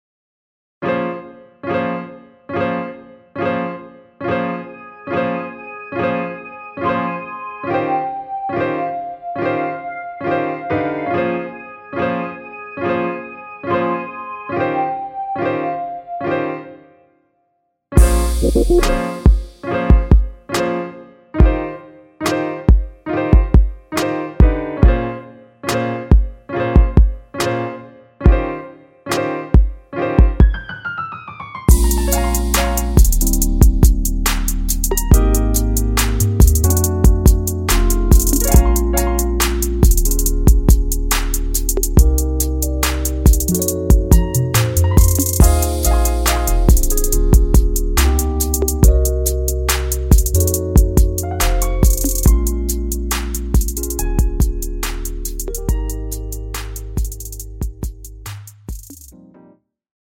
(-1) 내린 MR 입니다.(미리듣기 참조)
Ab
◈ 곡명 옆 (-1)은 반음 내림, (+1)은 반음 올림 입니다.
앞부분30초, 뒷부분30초씩 편집해서 올려 드리고 있습니다.